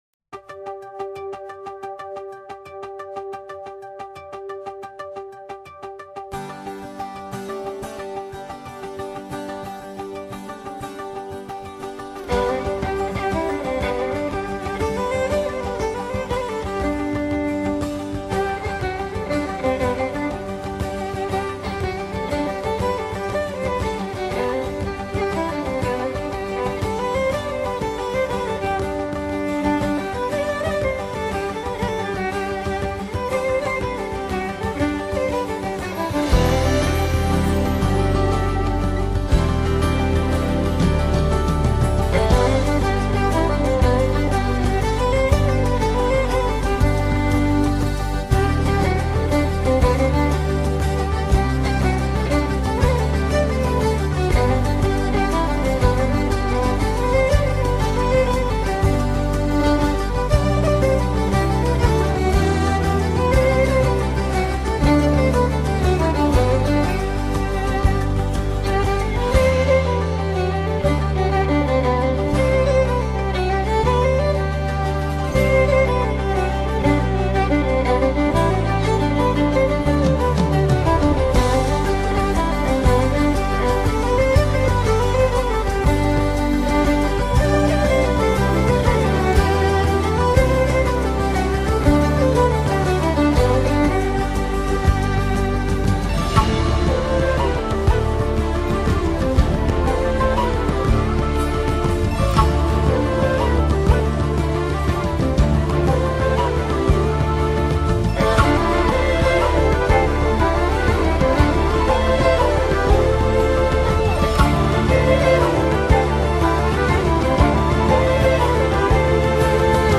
与前两张相比人声部分加大了